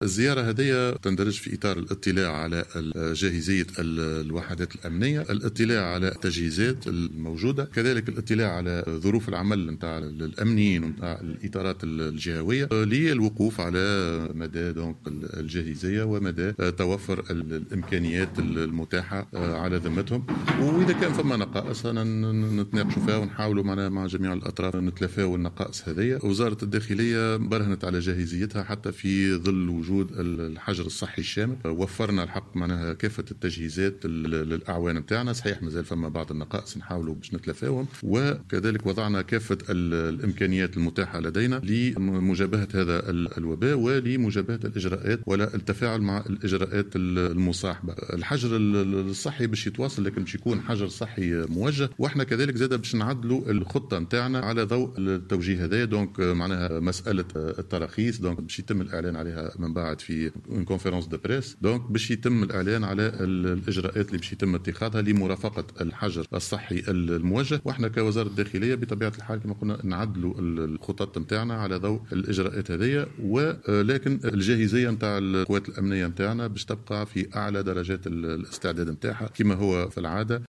وبيّن وزير الداخلية في تصريح للجوهرة أف–أم، خلال زيارته الى ولاية جندوبة للاطلاع على جاهزية الوحدات الأمنية وظروف العمل بالجهة، أن الوزارة على أتمّ الاستعداد للتفاعل مع الاجراءات الجديدة التي سيُعلن عنها اليوم بخصوص الحجر الصحي الموجه.